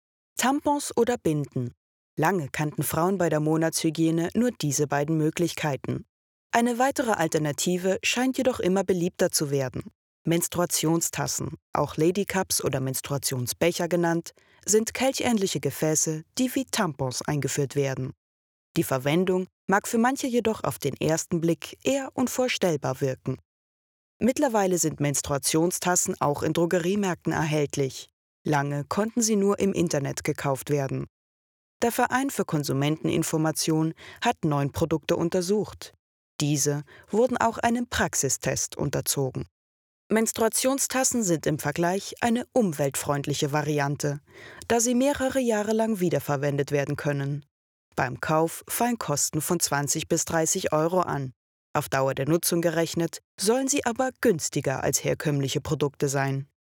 Ihre Stimme ist freundlich, sachlich/kompetent, über erzählerisch, aber auch jung, werblich, lieblich oder verführerisch.
freundlich, warm, wandelbar, frech und verführerisch
Sprechprobe: Industrie (Muttersprache):
Nachrichtenbeitrag.mp3